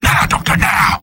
Robot-filtered lines from MvM.